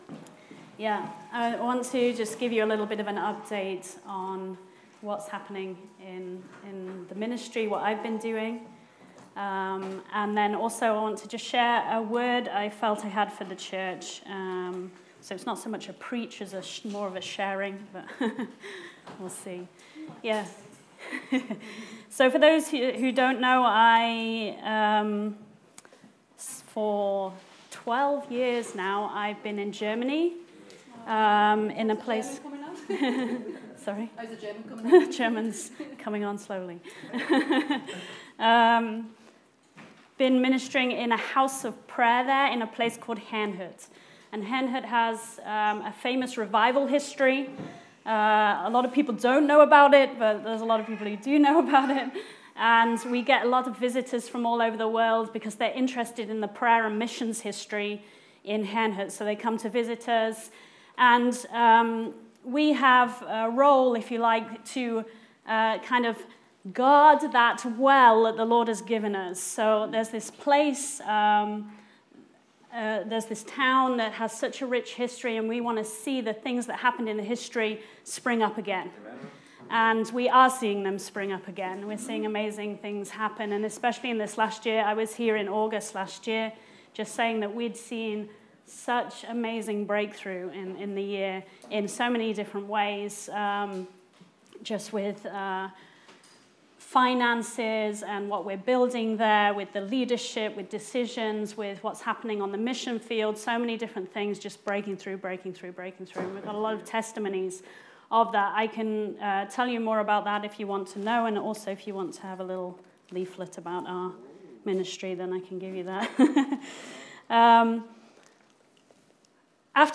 OCF Sermons